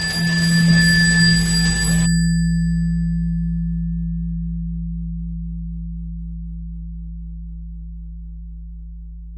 Descarga de Sonidos mp3 Gratis: sintetizador 13.
filtrada_1.mp3